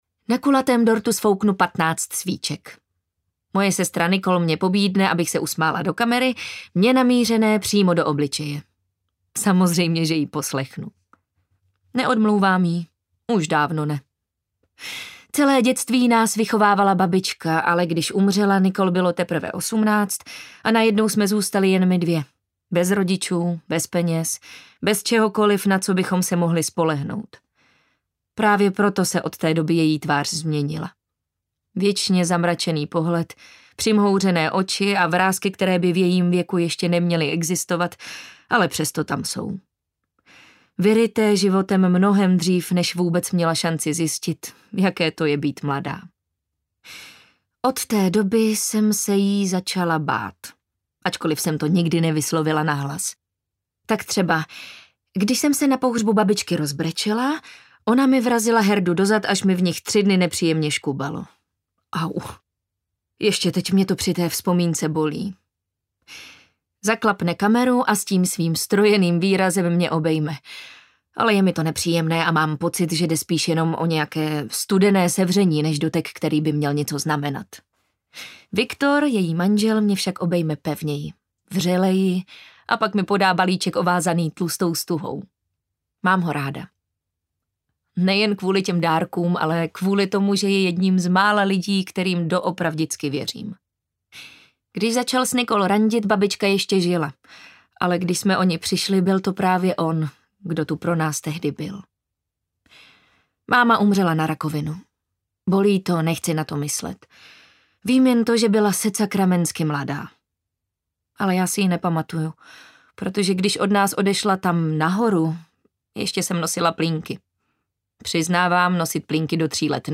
Pod Taktovkou audiokniha
Ukázka z knihy